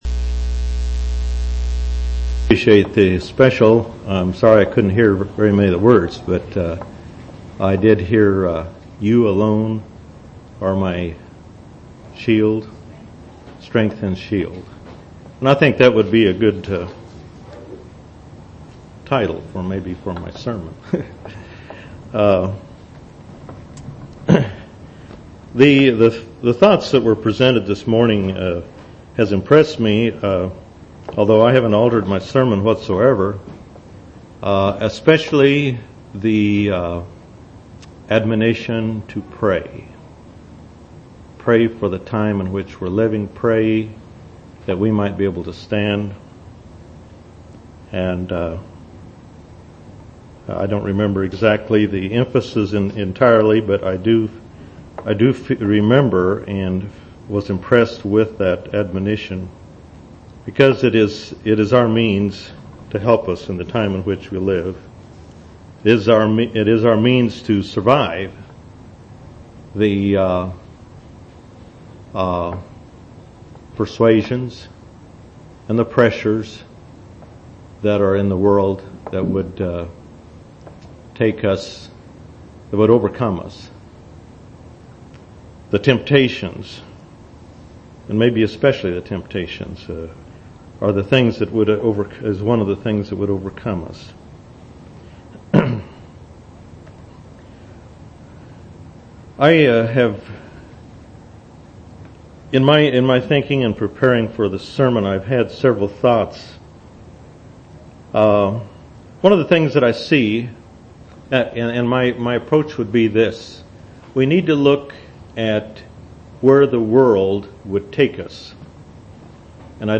8/20/2000 Location: East Independence Local Event